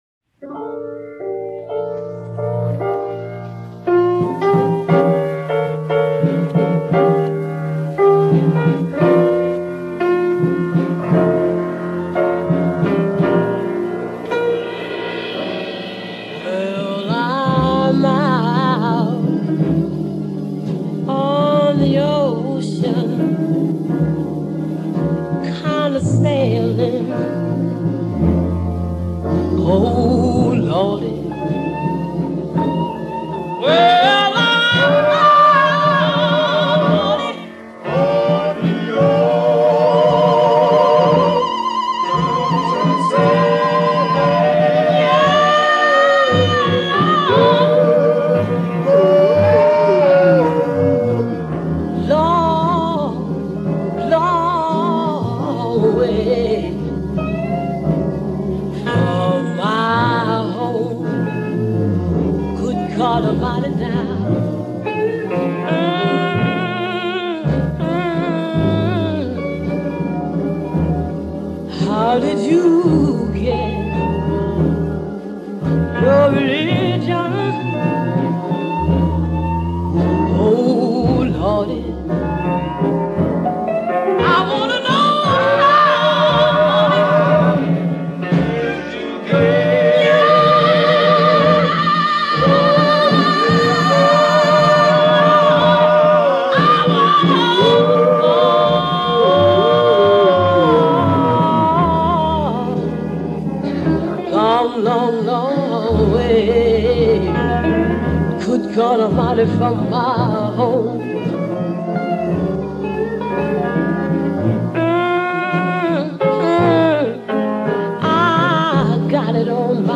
‘golden age’ Gospel